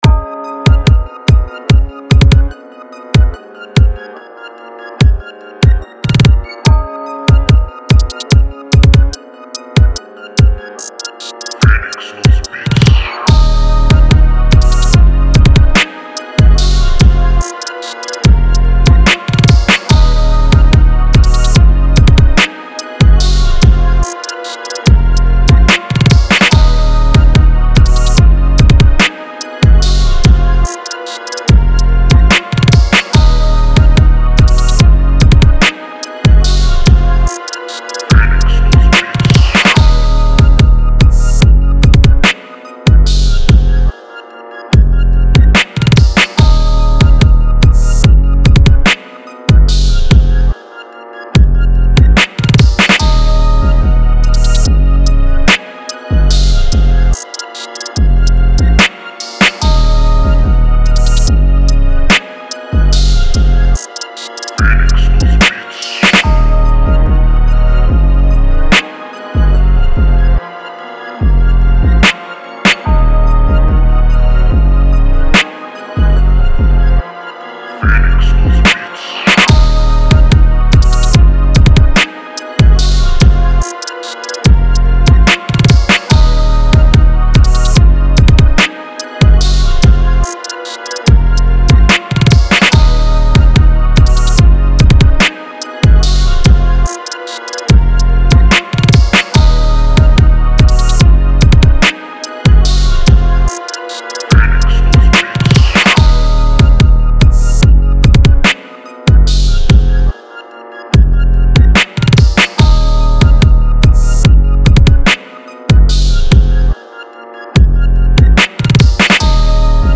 Aggressive Trap Instrumental